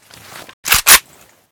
ak12_m1_misfire.ogg